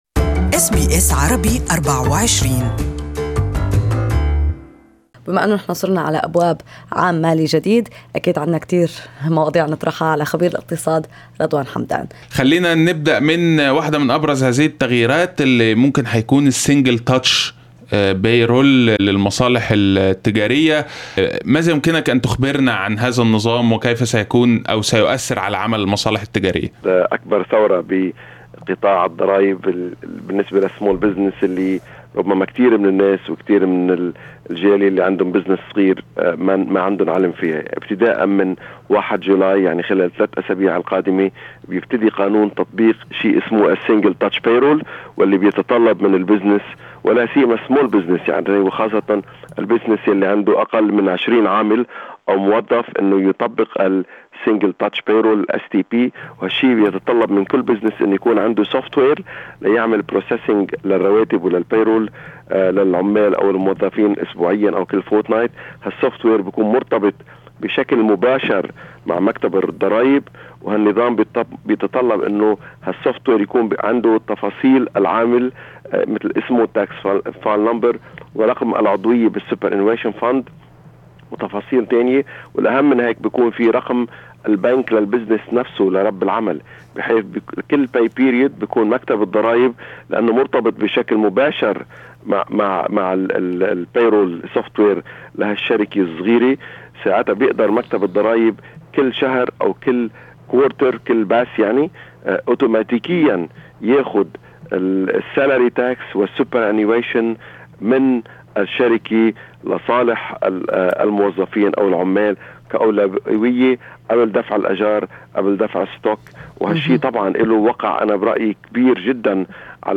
اللقاء الكامل